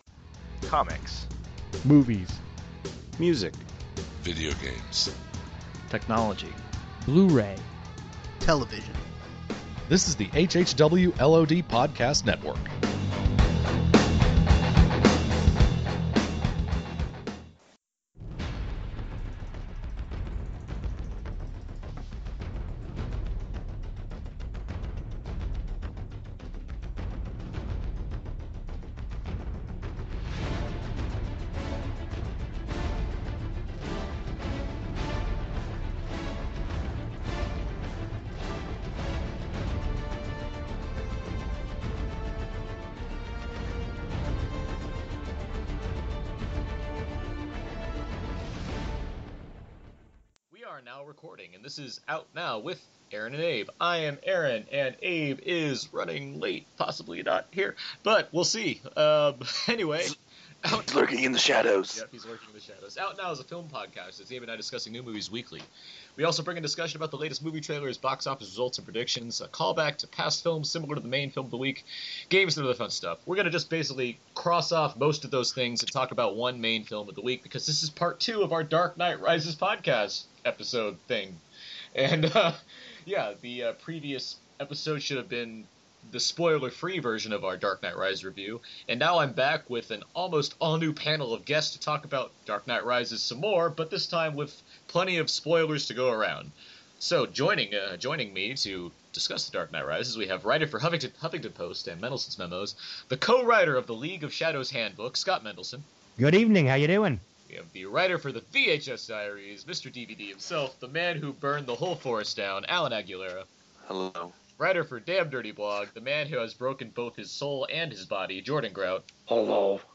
Out Now Bonus - A Spoiler-Filled Discussion of The Dark Knight Rises
Differing viewpoints certainly lead to some spirited conversing, but overall, everyone just wants to pick apart (in positive and negative ways) the most anticipated film of the year.